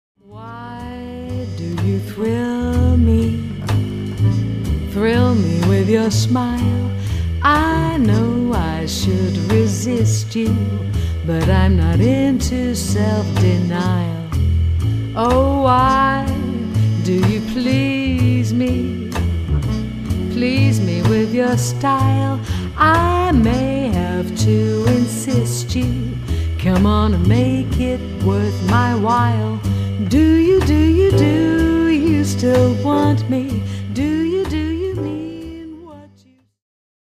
Vocals
Bass
Drums
Piano
Sax and Flute
Percussion
Trumpet and Flugel Horn